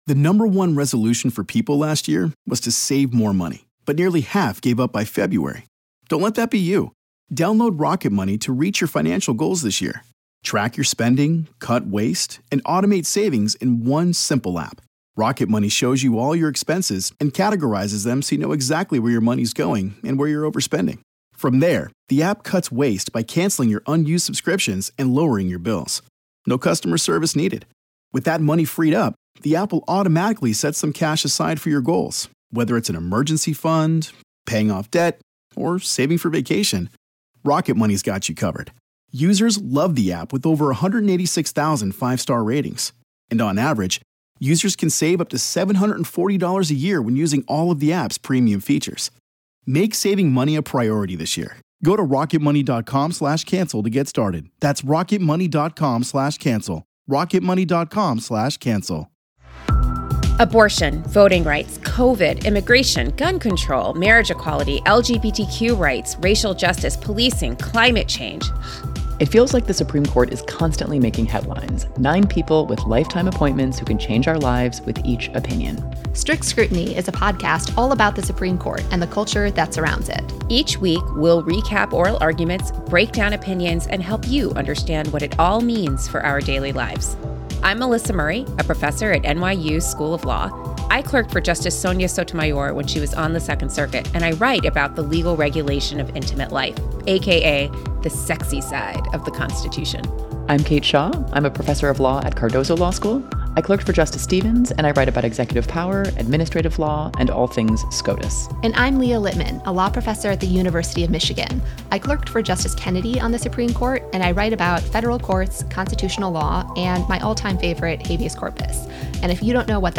They’re women who’ve practiced before and write about the Court in their professional lives.
They provide intelligent and in-depth legal analysis alongside their unvarnished, respectfully irreverent takes.